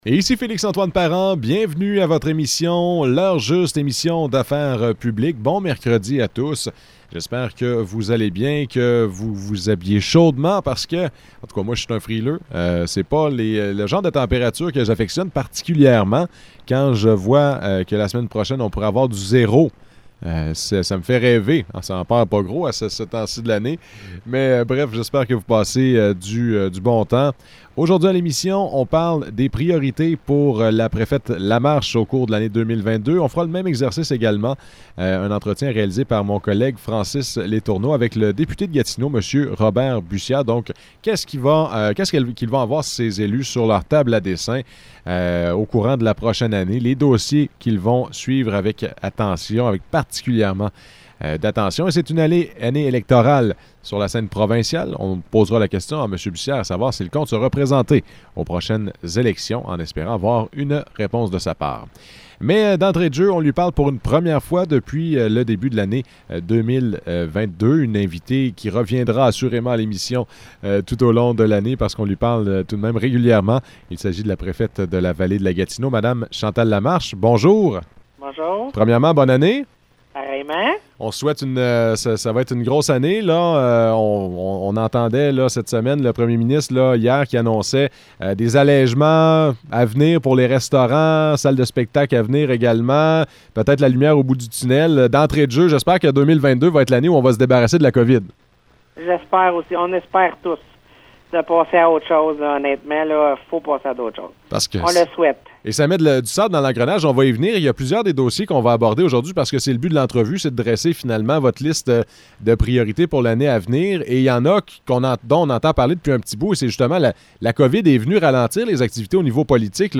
Entrevue avec Chantal Lamarche, préfète de la Vallée-de-la-Gatineau
Entrevues